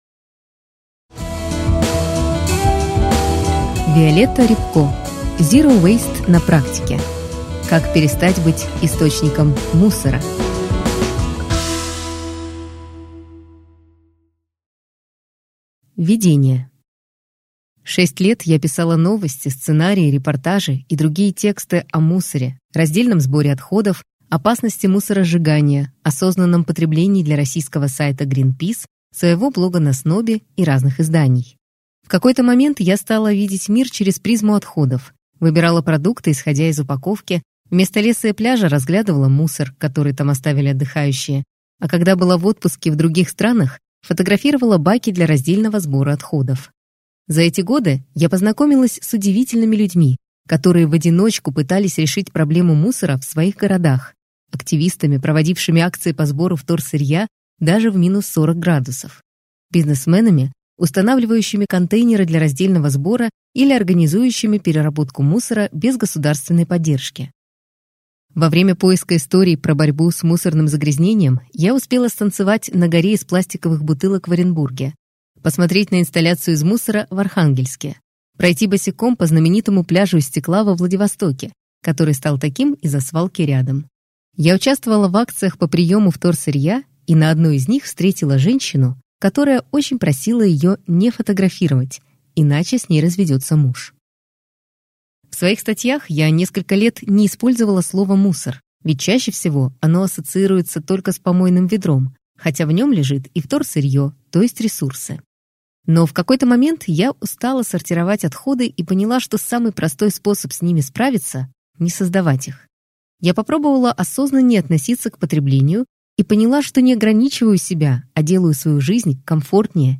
Аудиокнига Zero waste на практике. Как перестать быть источником мусора | Библиотека аудиокниг